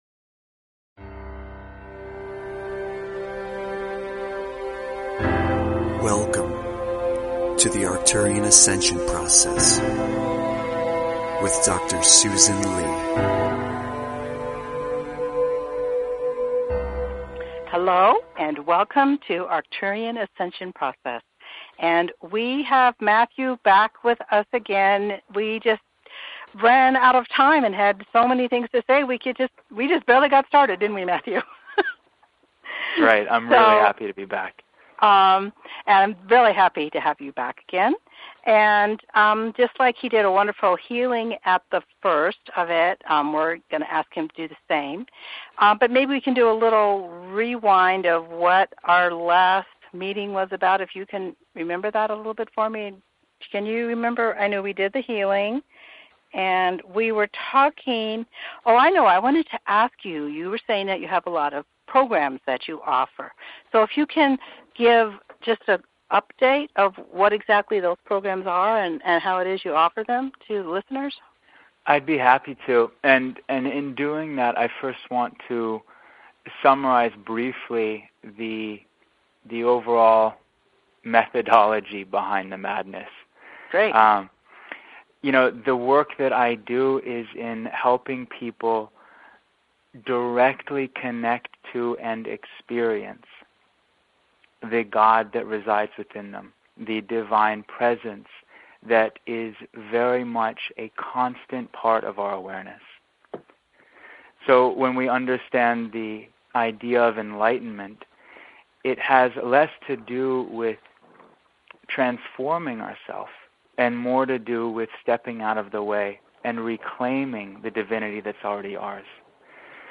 Talk Show Episode, Audio Podcast, Arcturian_Ascension_Process and Courtesy of BBS Radio on , show guests , about , categorized as